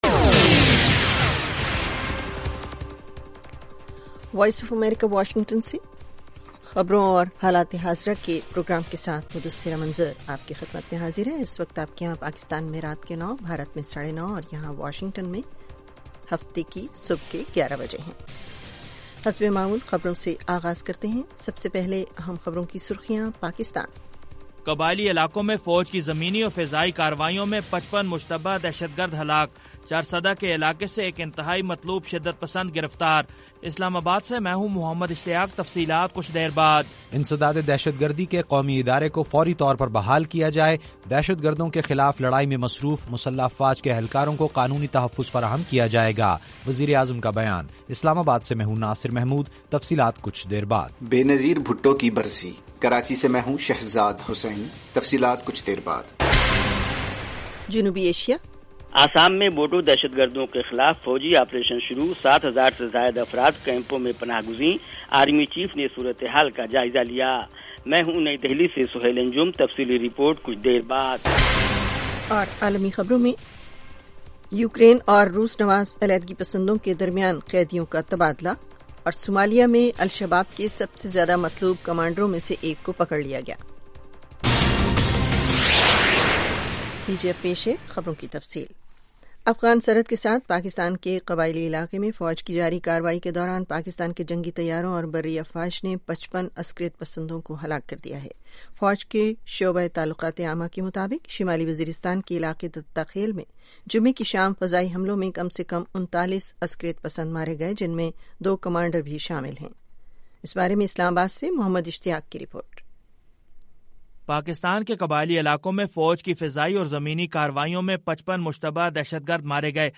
دن بھر کی اہم خبریں اور پاکستان اور بھارت سے ہمارے نمائندوں کی رپورٹیں۔ اس کے علاوہ انٹرویو، صحت، ادب و فن، کھیل، سائنس اور ٹیکنالوجی اور دوسرے موضوعات کا احاطہ۔